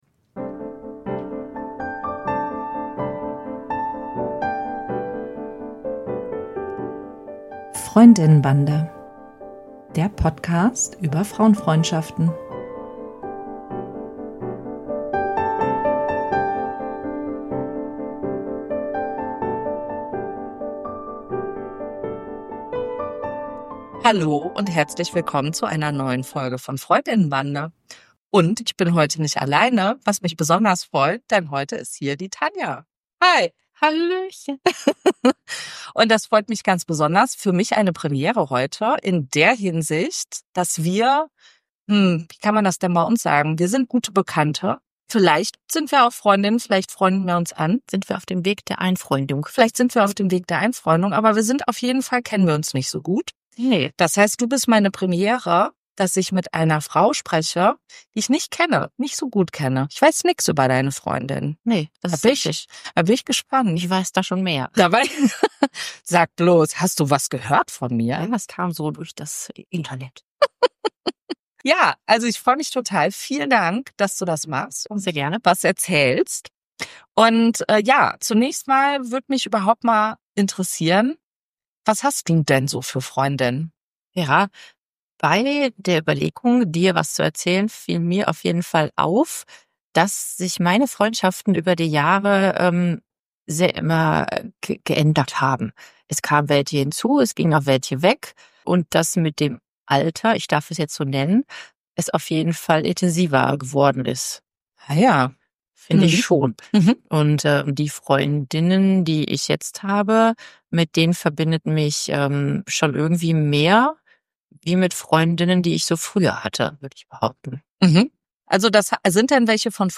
Meine Premiere mit einer Gesprächspartnerin, die nicht eine meiner besten Freundinnen ist.